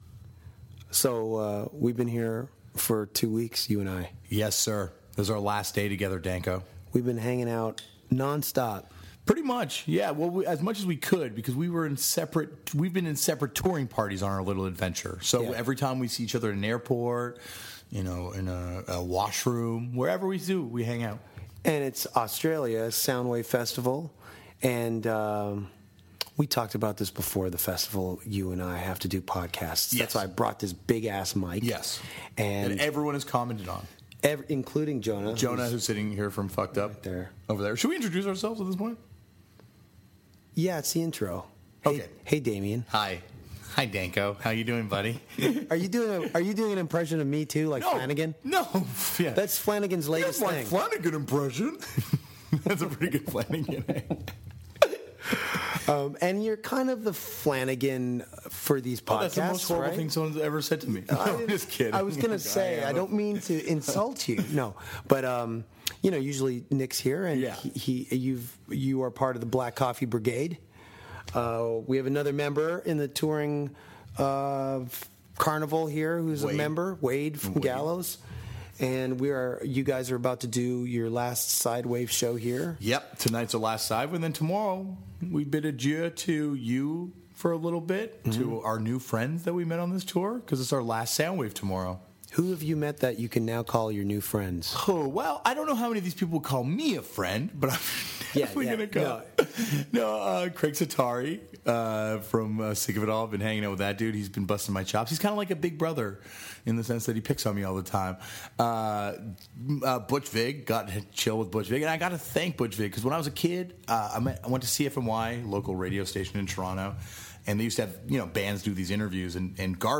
Danko and Damian Abraham (Fucked Up) tag team in Sydney, Australia for a sit-down with WWE legend, Chris Jericho, to talk about Fozzy and professional wrestling.